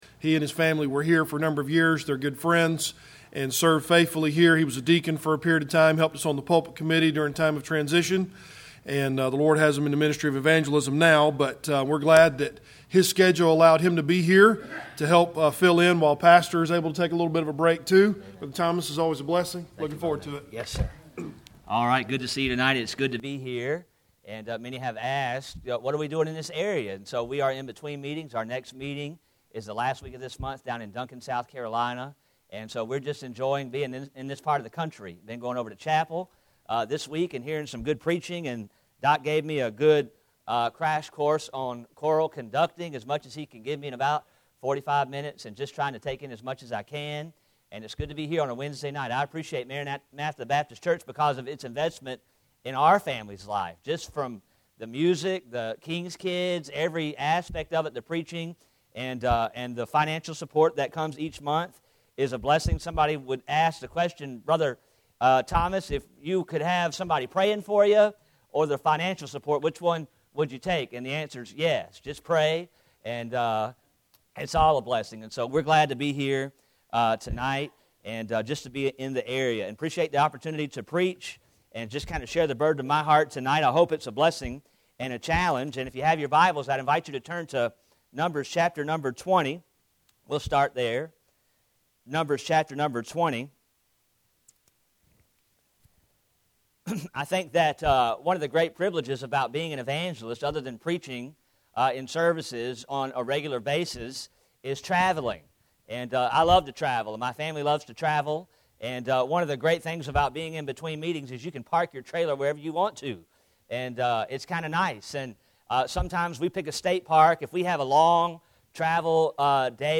Passage: Numbers 20:10-13 Service Type: Midweek Service Bible Text